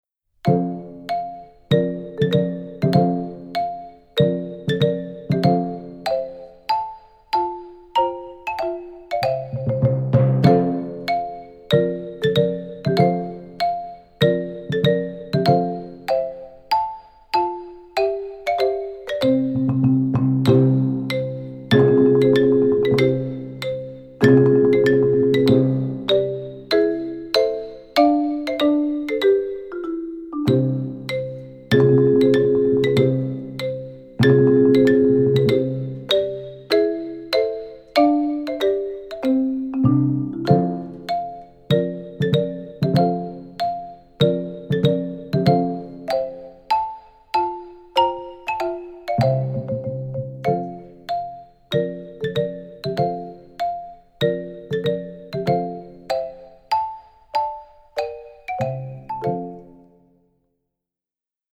Voicing: Percussion Quartet